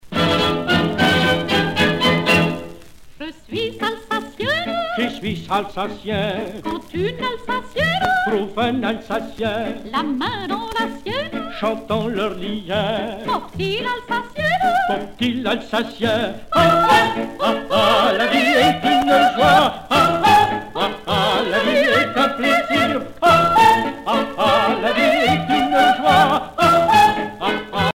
valse alsacienne